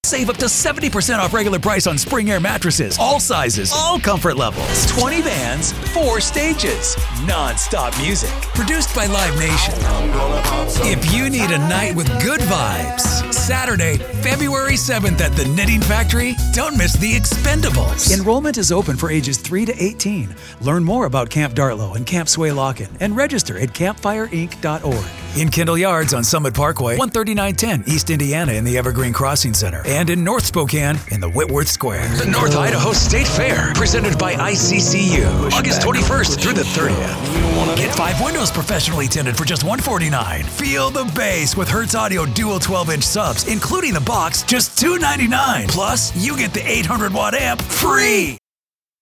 Passionate voice that captures listener attention
English - USA and Canada
Middle Aged
Commercial
Promo